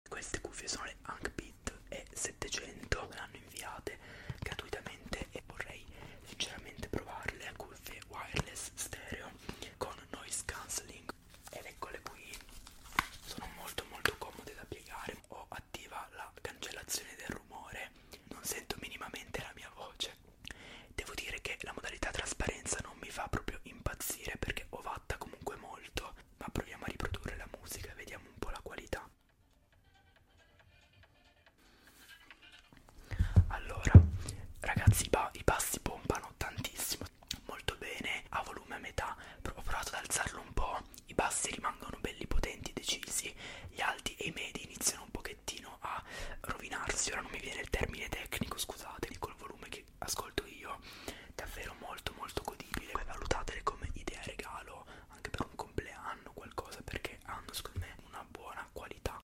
Ankbit E700 ASMR Rewiew | sound effects free download